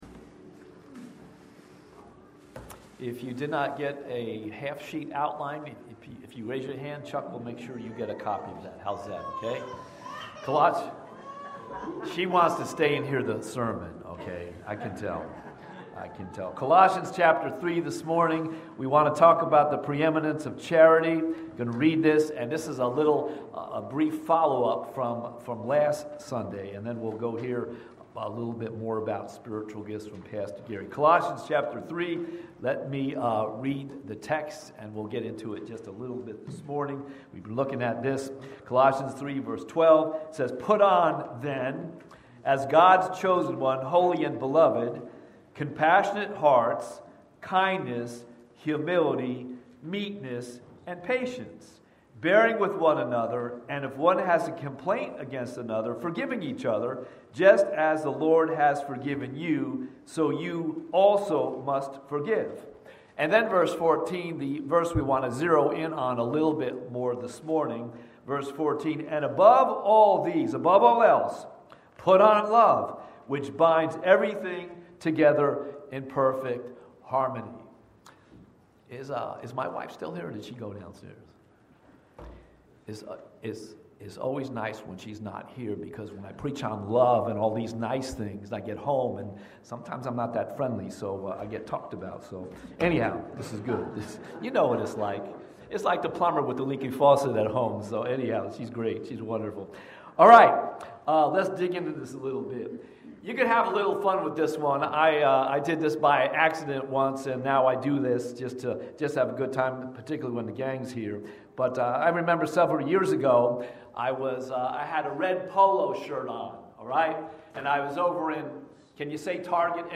Watch Online Service recorded at 9:45 Sunday morning.
Sermon Audio